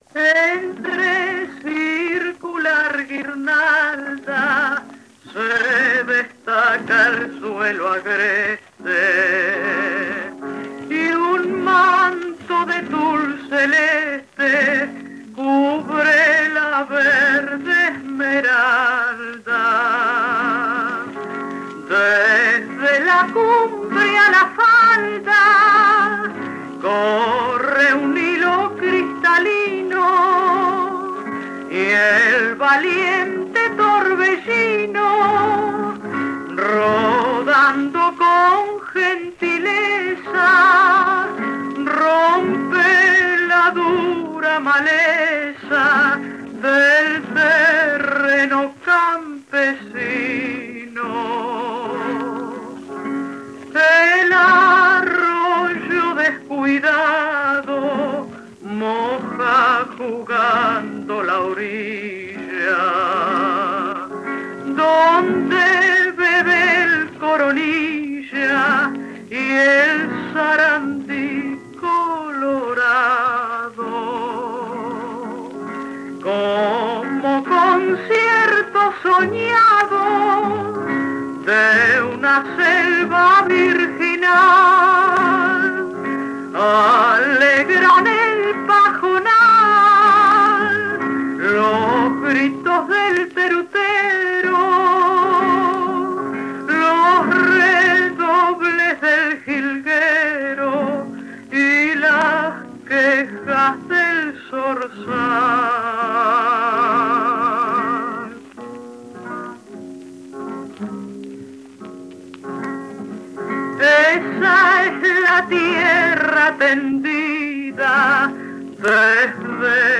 Ejecutante, edad, instrumental: Amalia de la Vega, 35 años, canto
Formato original de la grabación: disco de acetato Audiodisc de 25 cm de base metálica a 78 rpm